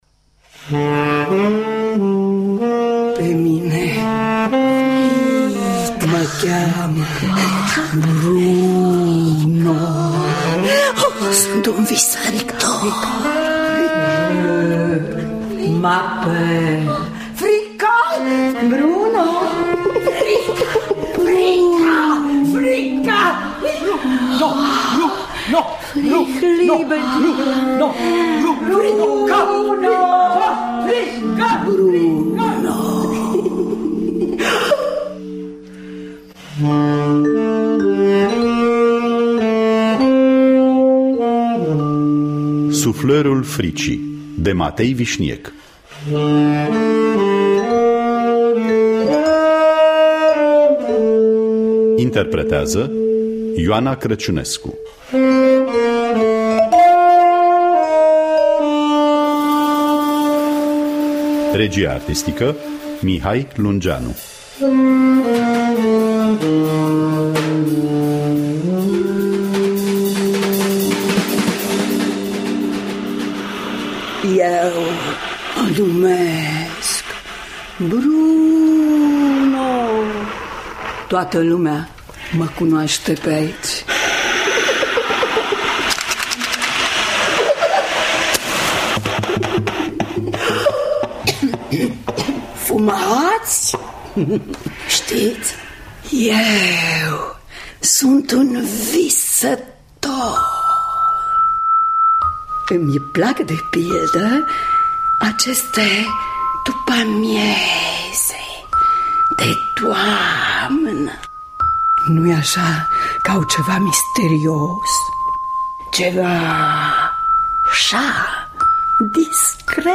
Interpretează: Ioana Crăciunescu.
saxofon
vibrafon
percuţie